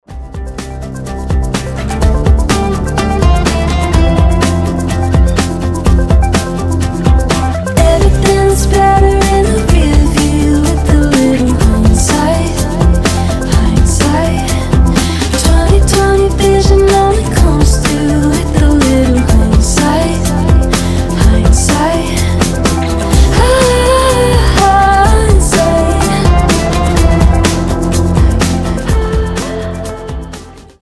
• Качество: 320, Stereo
гитара
милые
красивый женский голос
indie pop